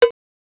LM-1_COWBELL_TL.wav